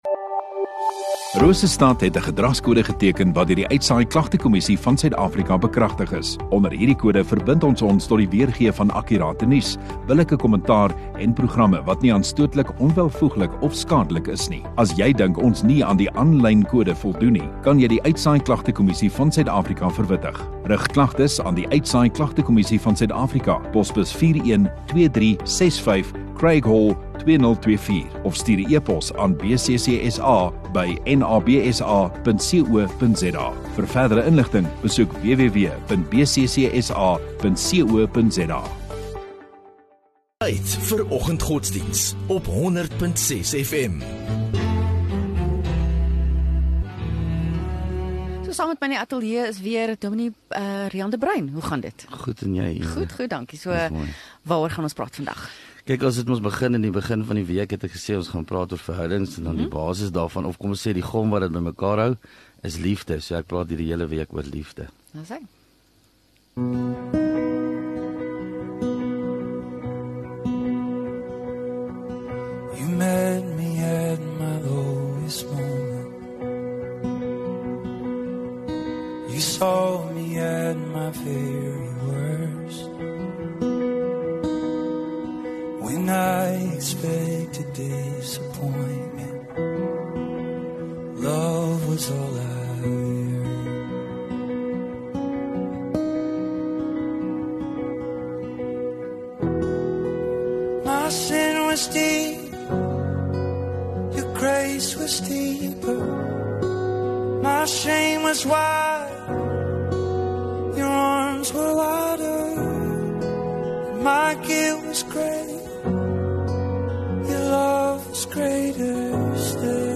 View Promo Continue Install Rosestad Godsdiens 17 Jul Woensdag Oggenddiens